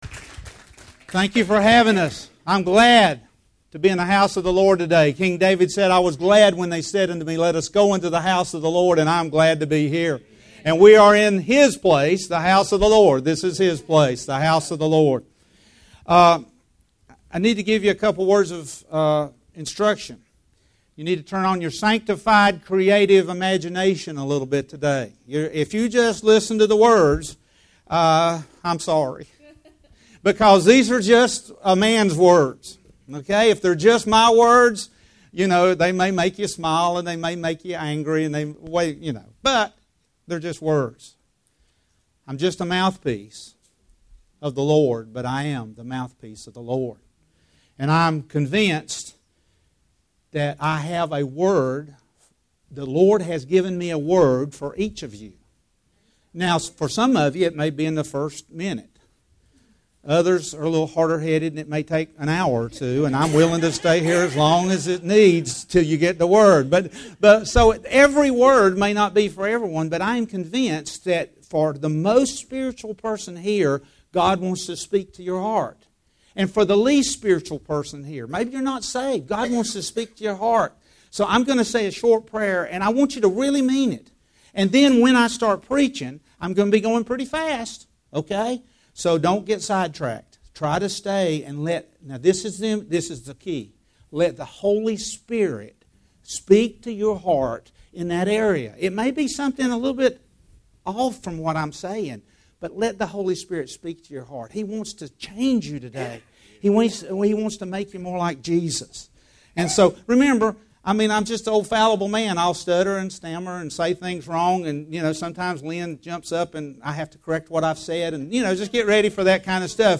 Sermons Archive - His Place Worship Center